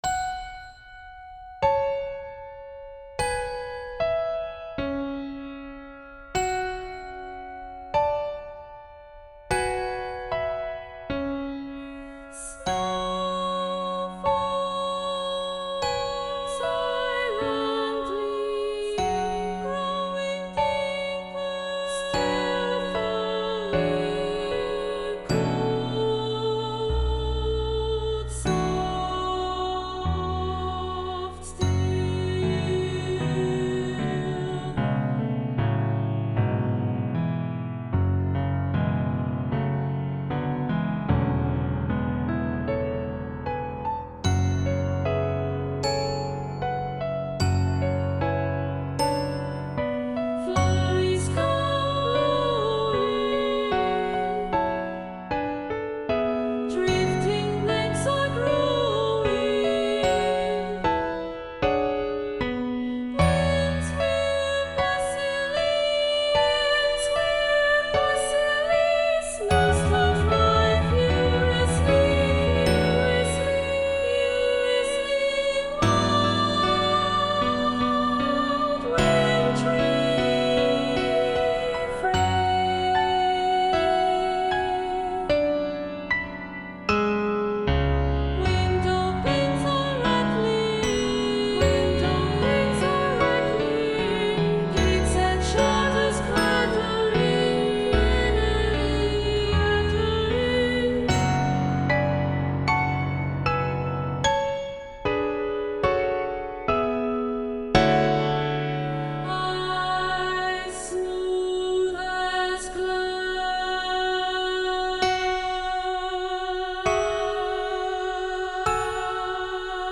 Instrumente: Klavier (1) ; Handglocken oder Glockenspiel
Tonart(en): fis-moll